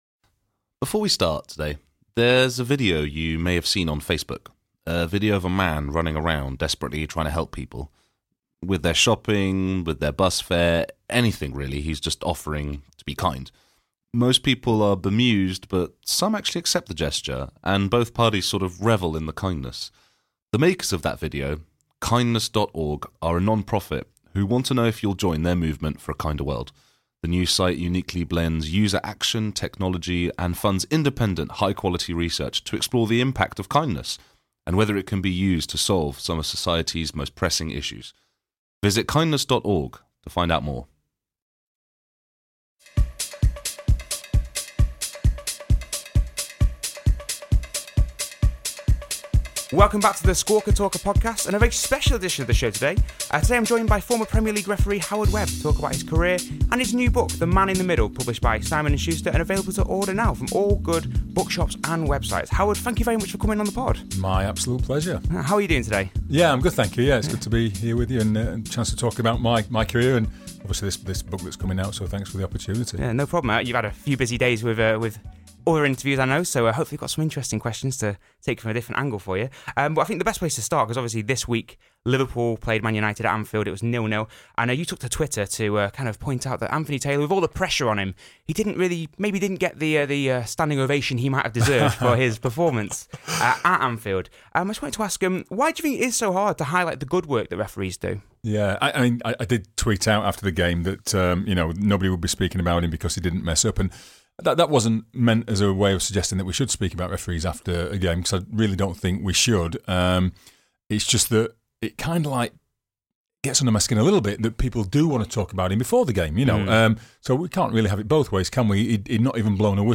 Interview with Howard Webb - The Man In The Middle